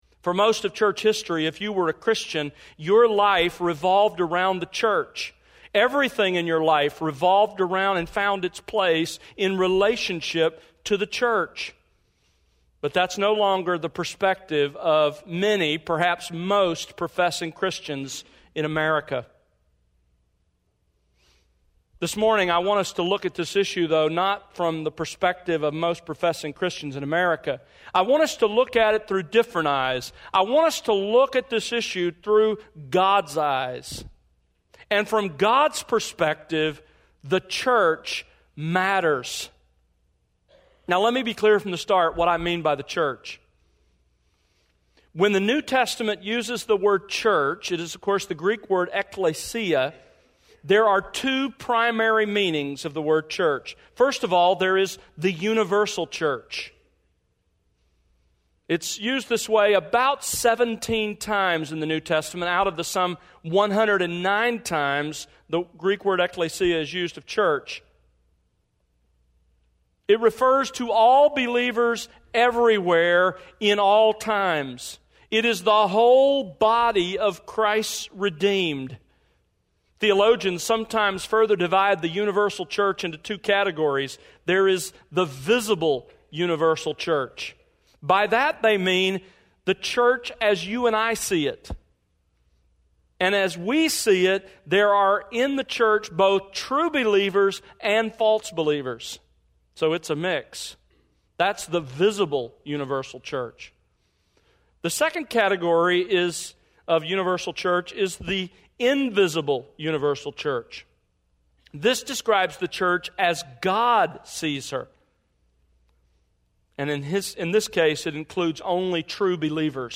Sermons | The World Unleashed